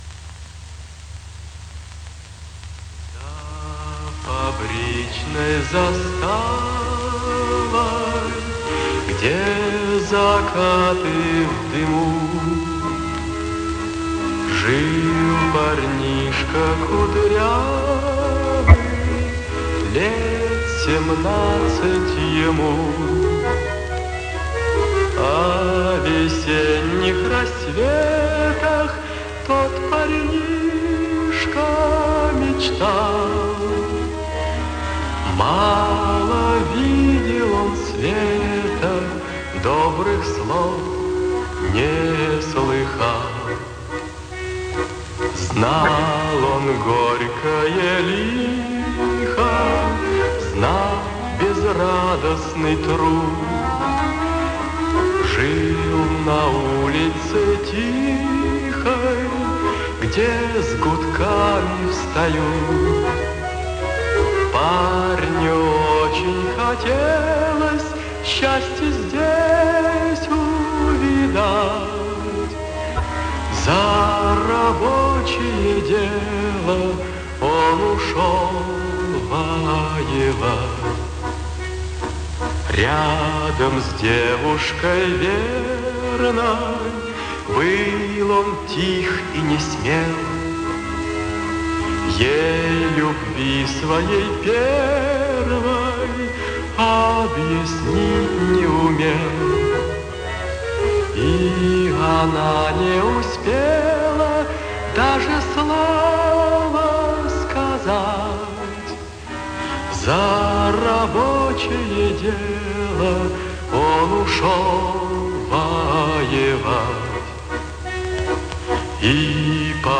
саундтрек из кино